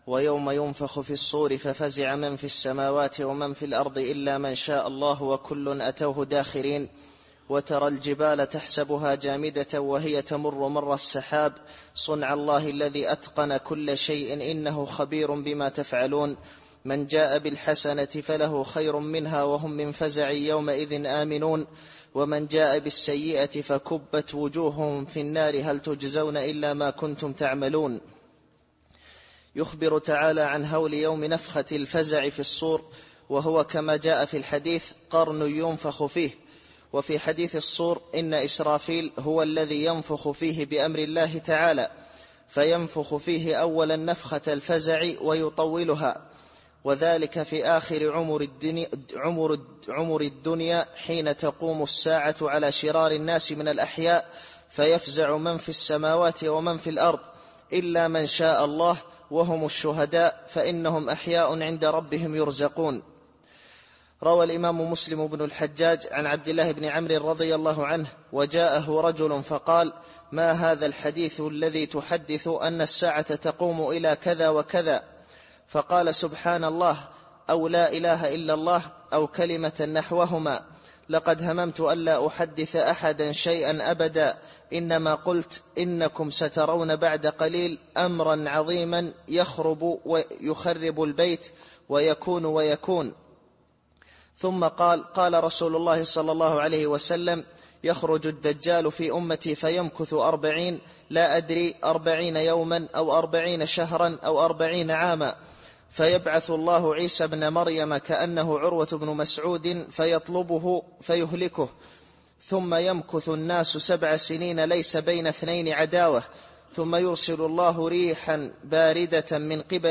التفسير الصوتي [النمل / 87]